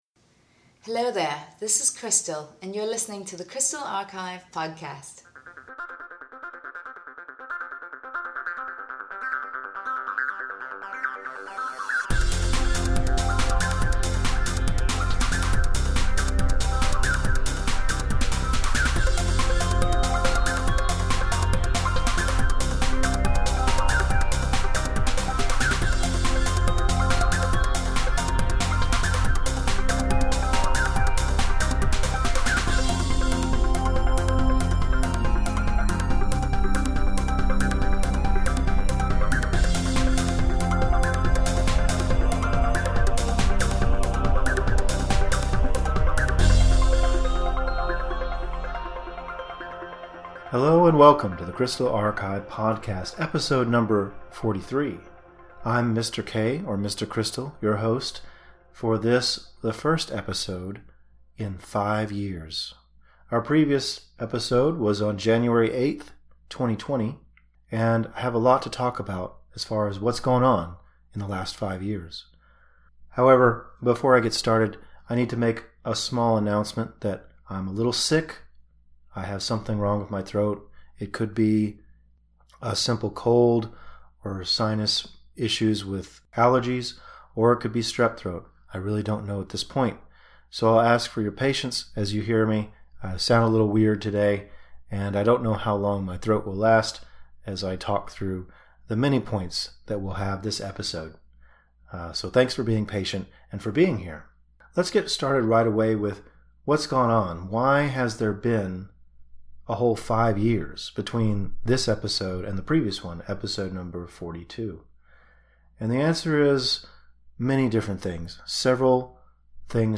Recorded on January 11th, 2025, I did this episode while sick, so my voice sounds very rough.